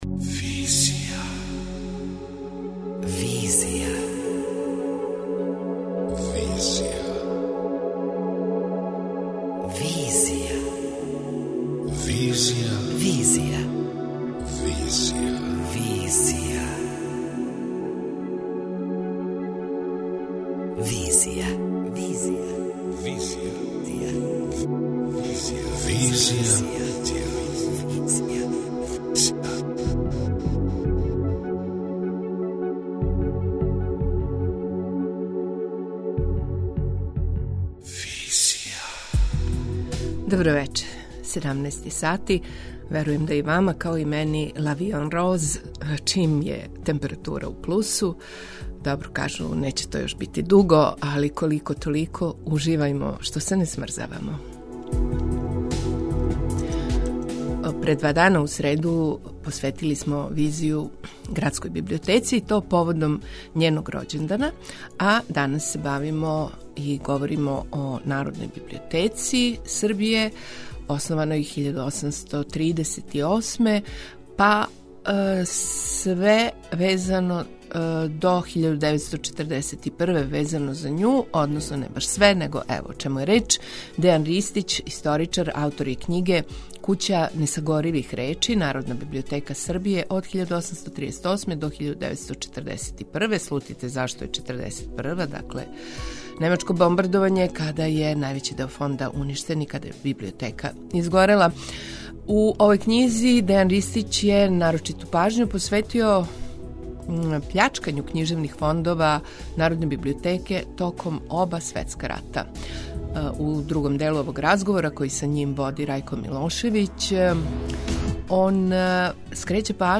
преузми : 28.20 MB Визија Autor: Београд 202 Социо-културолошки магазин, који прати савремене друштвене феномене.